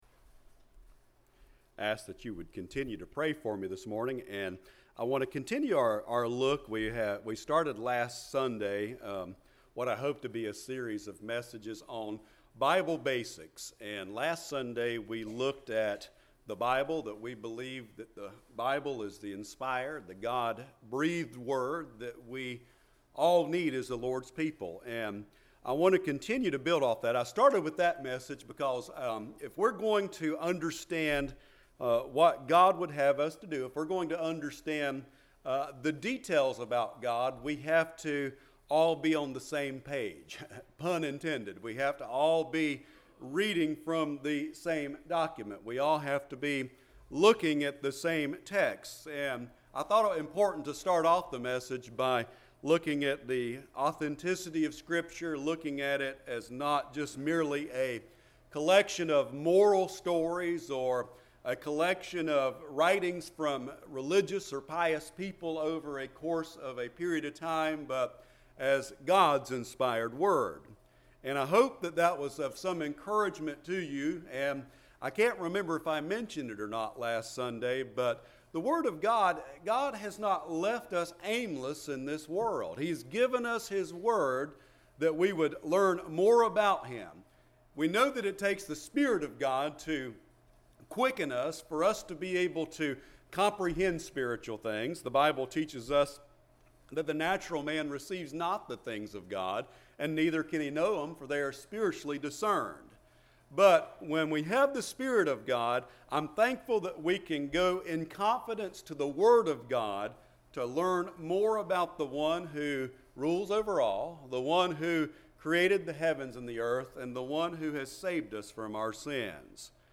06/18/2023 Sunday Morning
Service Type: Sunday Morning